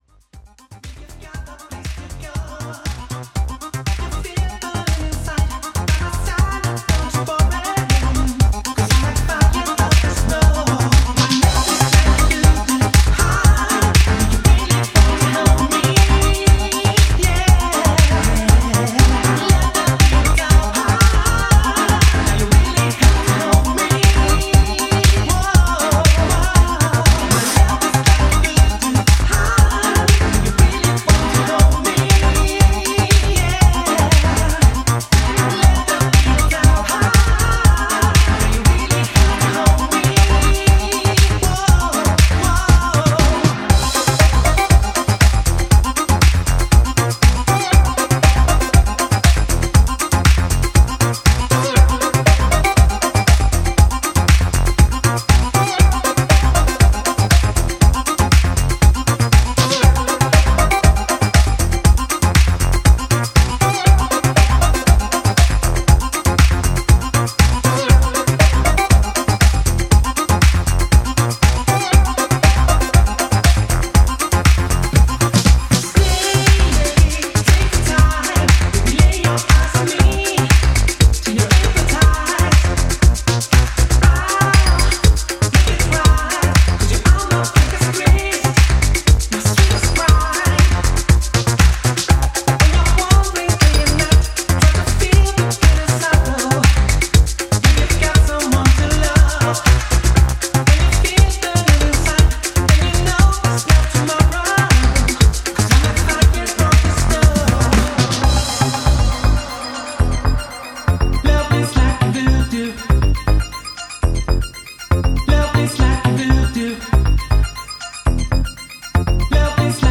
italo-nu-disco